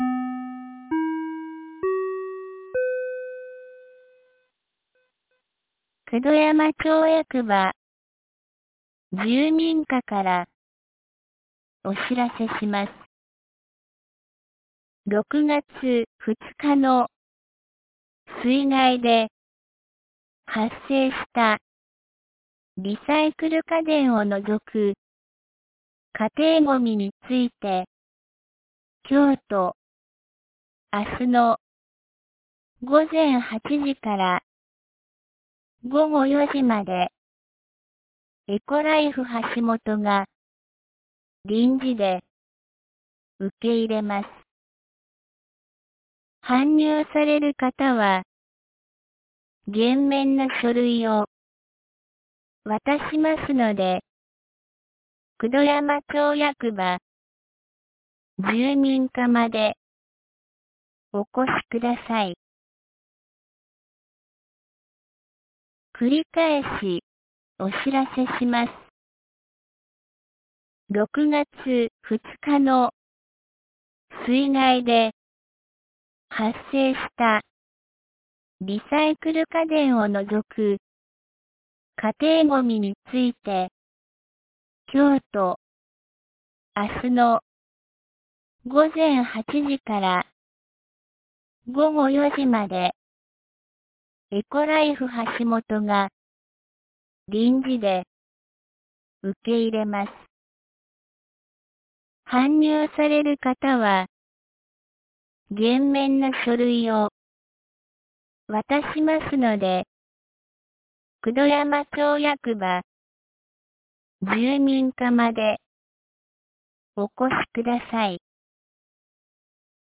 2023年06月03日 10時12分に、九度山町より全地区へ放送がありました。
放送音声